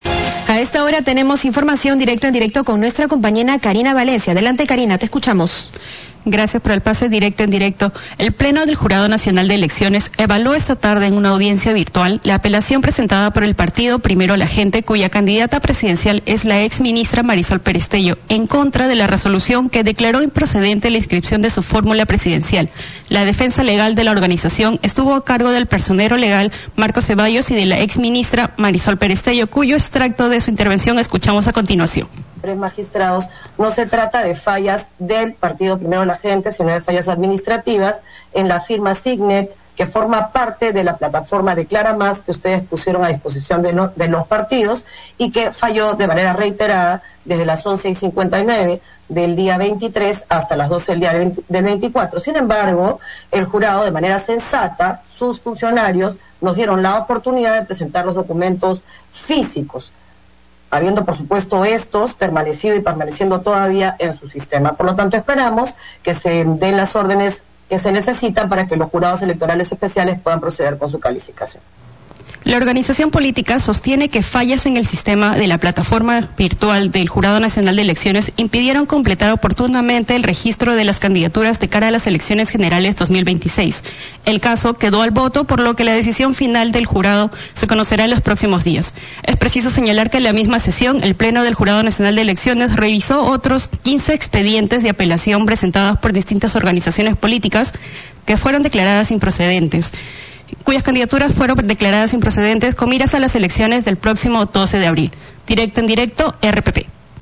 El Pleno del Jurado Nacional de Elecciones evalúo en audiencia virtual la apelación presentada por el Partido Primero La Gente cuya candidata presidencial es la exministra Marisol Pérez Tello en contra de la resolución que declare improcedente la inscripción de su formula presidencial.